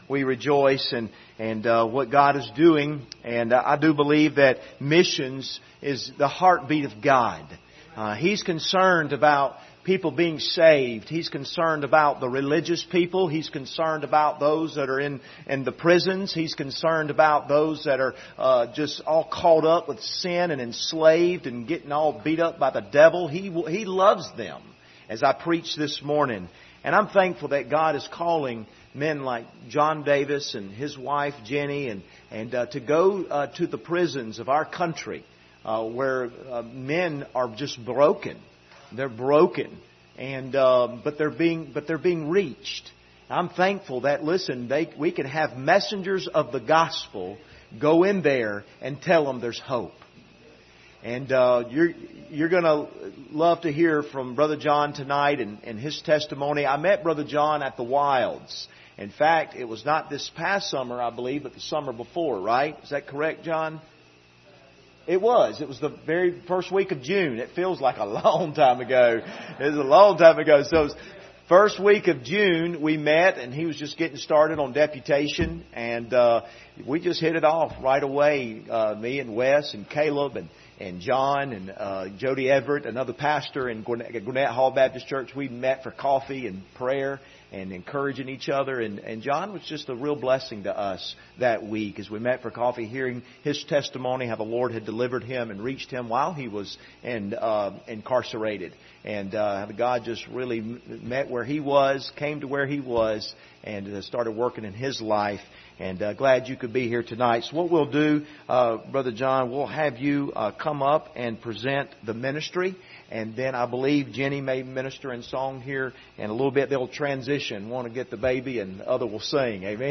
Passage: 1 Peter 2:11-12 Service Type: Sunday Evening